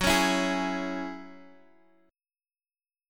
F#7sus2 chord